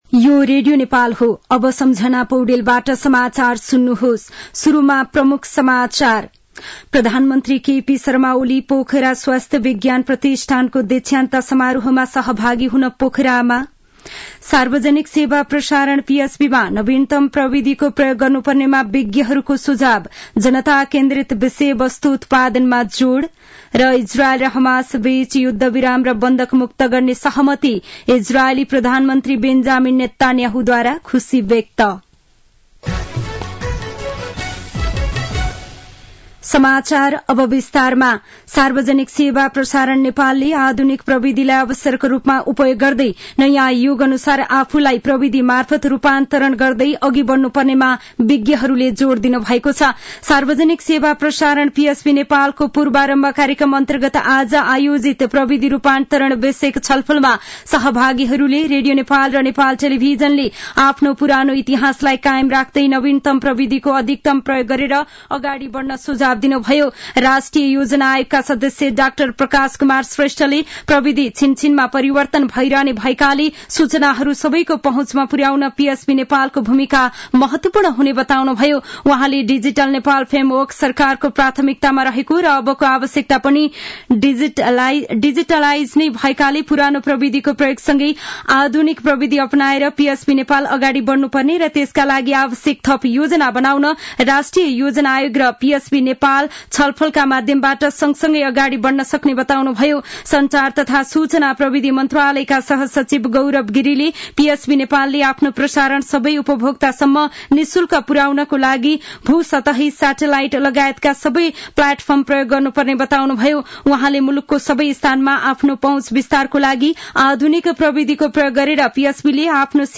दिउँसो ३ बजेको नेपाली समाचार : ४ माघ , २०८१
3-pm-news-1-5.mp3